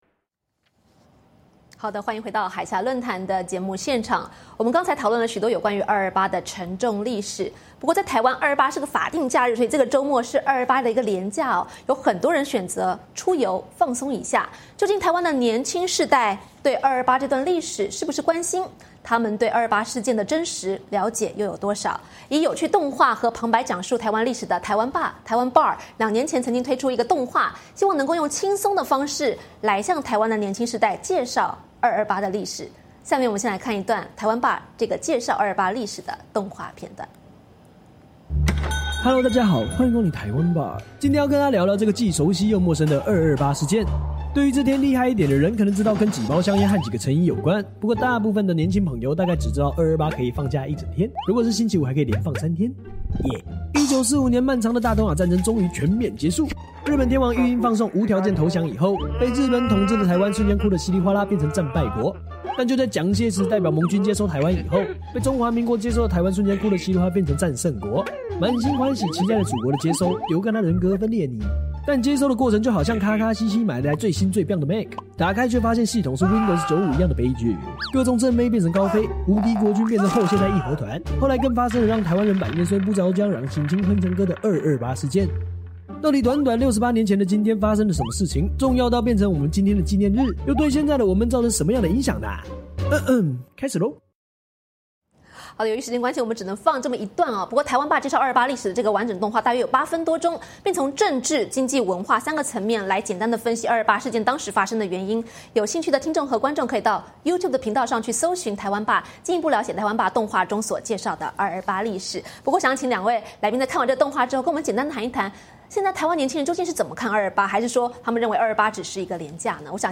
《海峡论谈二二八70周年系列》第二集，邀请曾在台湾多所大学任教的王丹教授以及时事评论员侯汉廷先生跟大家分享台湾年轻世代如何看待二二八事件。